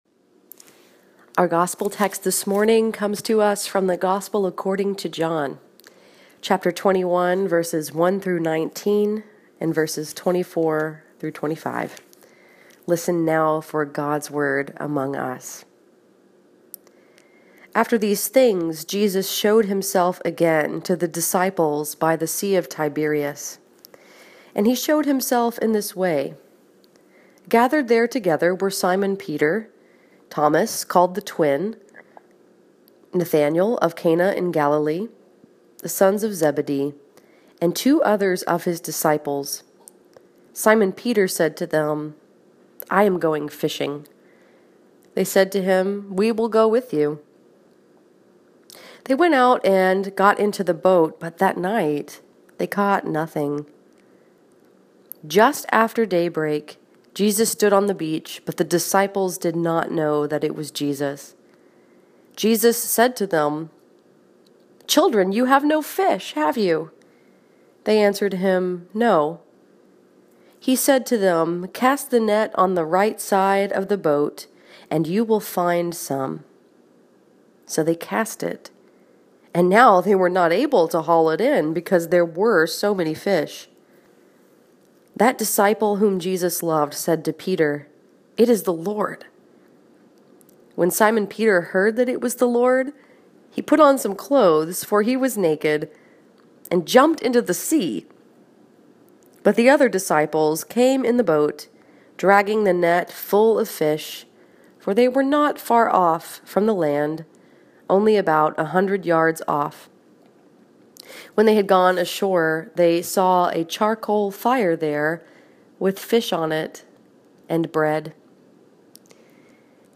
This sermon was preached at St. Andrew’s Presbyterian Church in Dearborn Heights, Michigan and was focused upon the story that is told in John 21:1-19, 24-25.